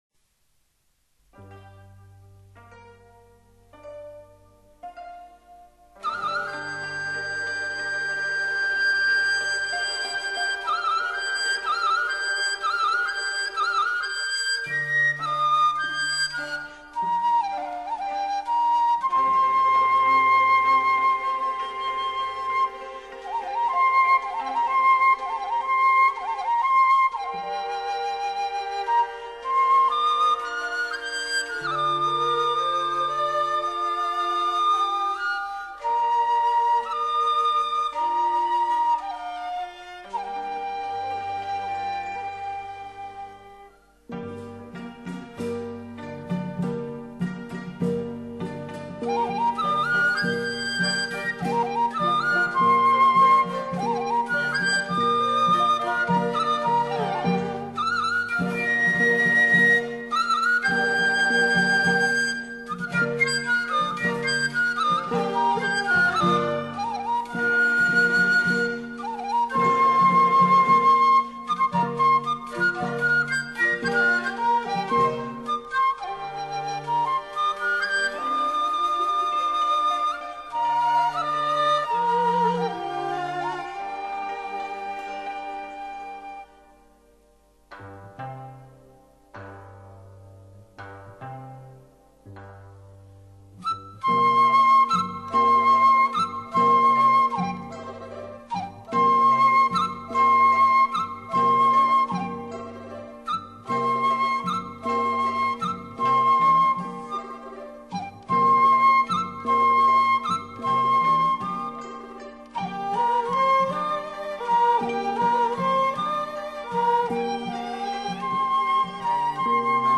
吐良独奏